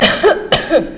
Woman Coughing Sound Effect
Download a high-quality woman coughing sound effect.
woman-coughing.mp3